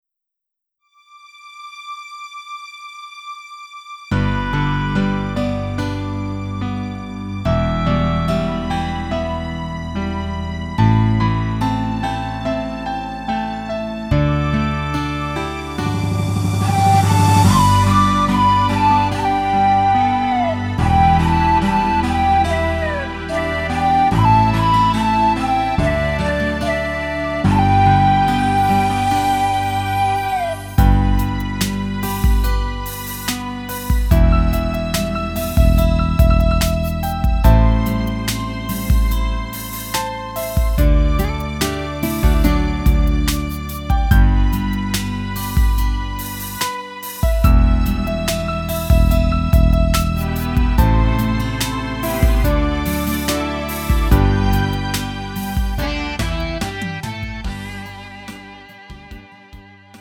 음정 -1키 3:39
장르 구분 Lite MR